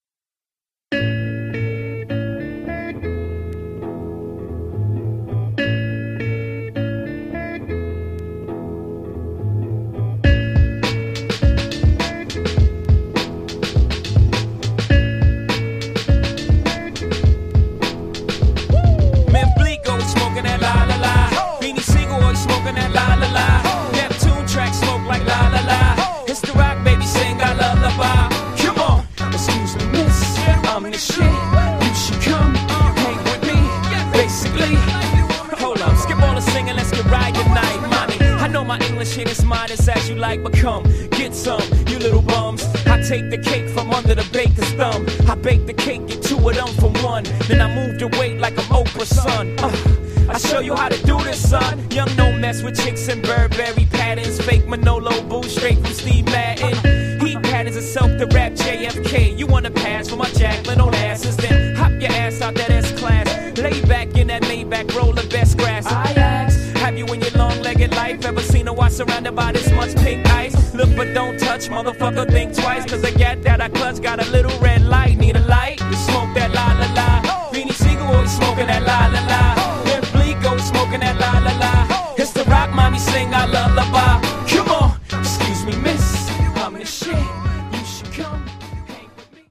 103 bpm
Dirty Version